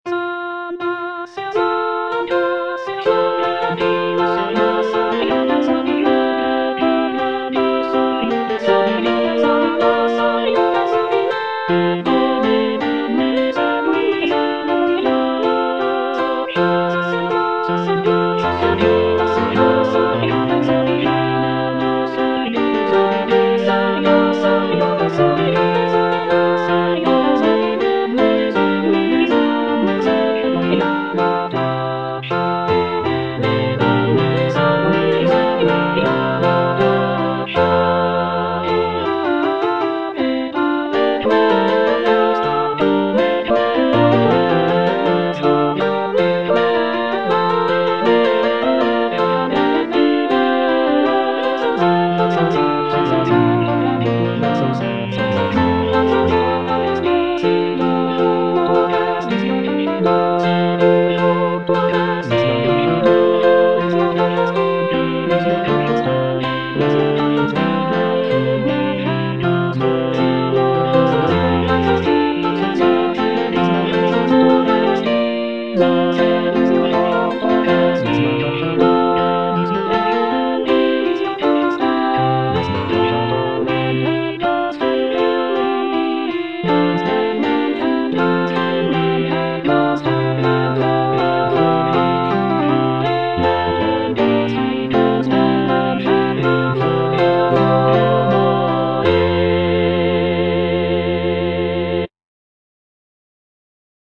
C. MONTEVERDI - S'ANDASSE AMOR A CACCIA (All voices) Ads stop:
"S'andasse Amor a caccia" is a madrigal composed by Claudio Monteverdi, an Italian composer from the late Renaissance period.